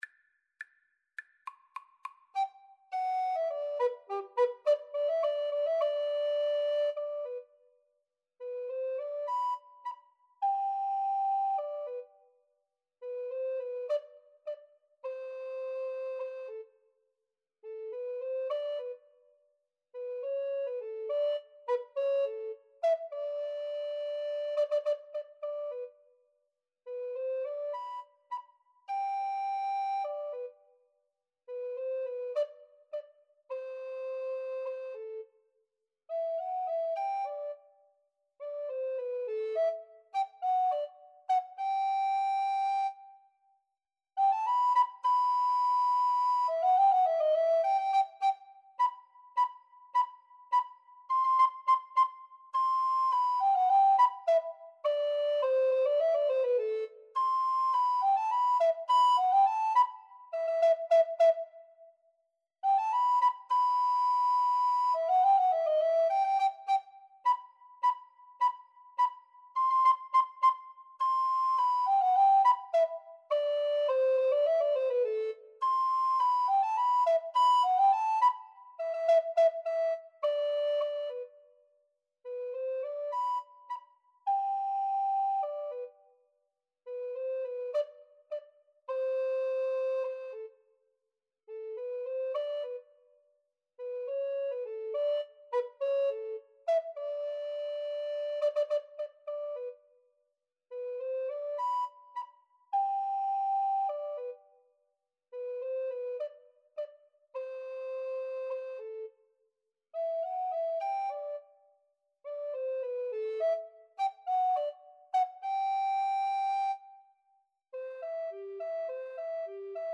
Alto RecorderTenor Recorder
Quick March = c.104
Classical (View more Classical Recorder Duet Music)